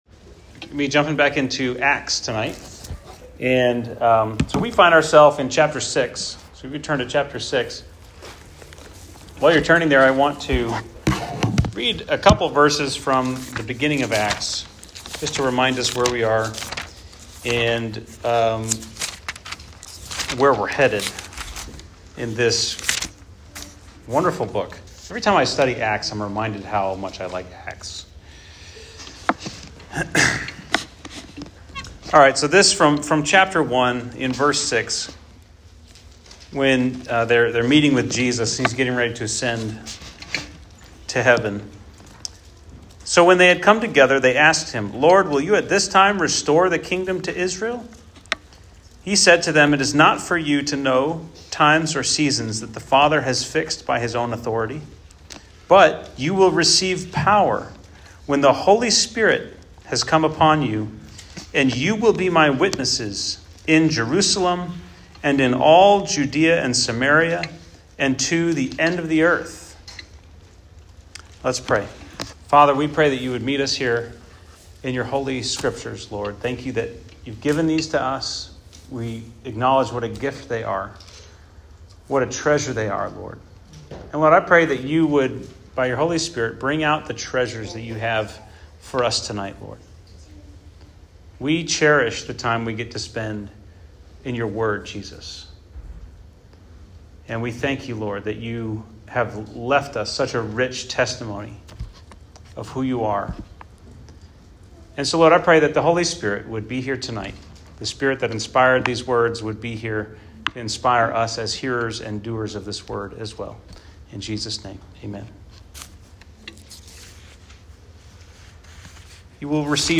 Weekly sermon audio from Emmanuel Christian Fellowship in Lexington, KY.